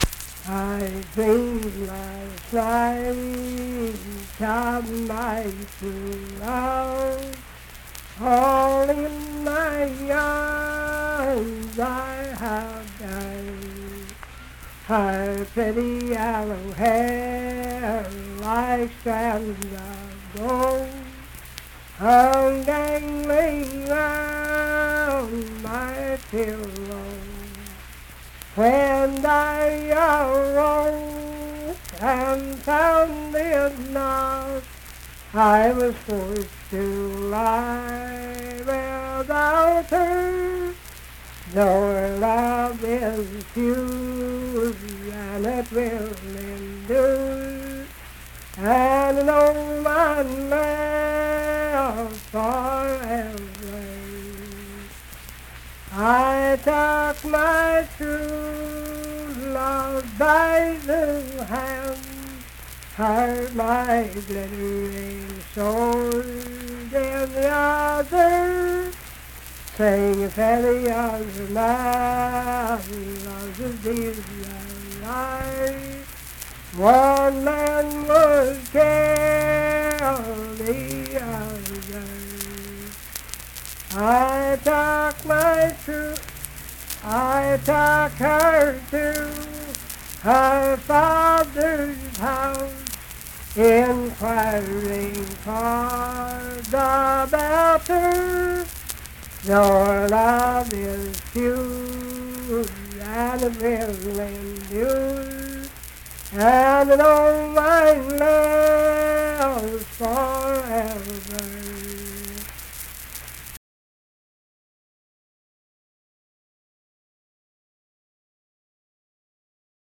Unaccompanied vocal music
Verse-refrain 4(4).
Performed in Ivydale, Clay County, WV.
Voice (sung)